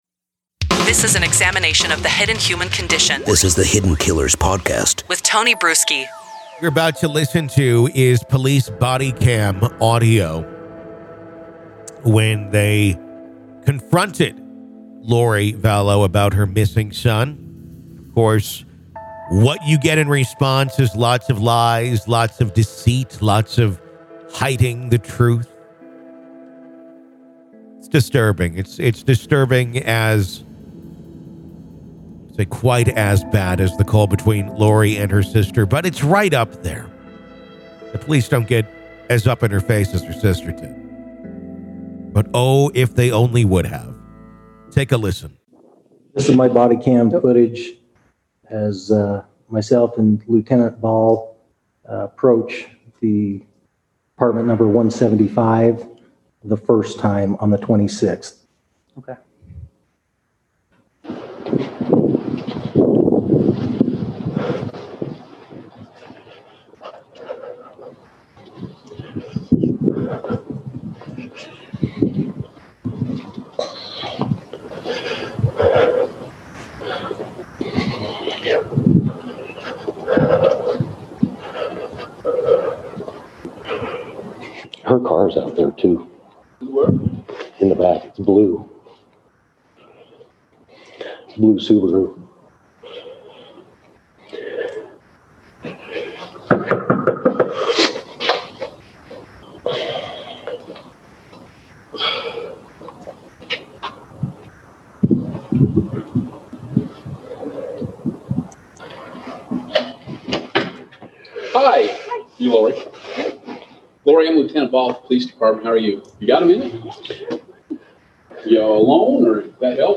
In a chilling development, newly released bodycam audio captures the moment police confront Lori Vallow about her missing son, revealing a twisted web of evil lies and deception.